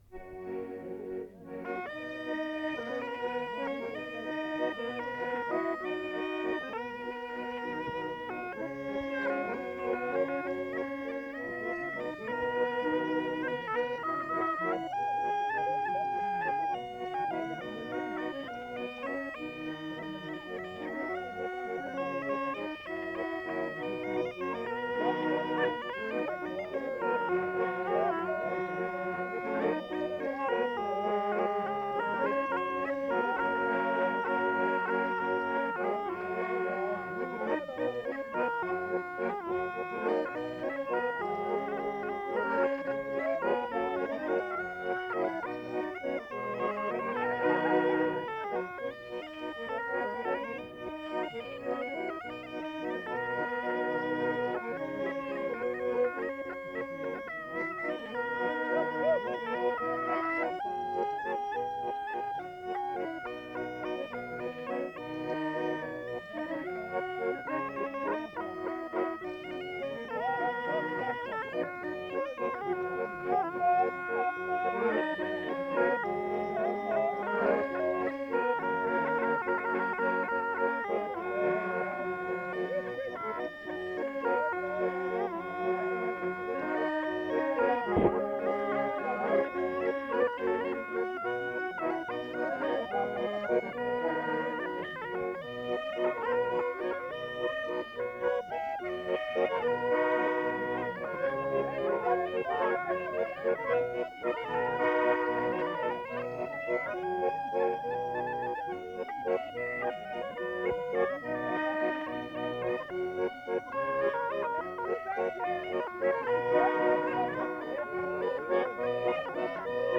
Valse
Aire culturelle : Viadène
Département : Aveyron
Genre : morceau instrumental
Instrument de musique : cabrette ; accordéon chromatique
Danse : valse
Notes consultables : Au moins deux airs différents enchaînés dont Etoile des neiges.
• [enquêtes sonores] Veillée Aligot au Quié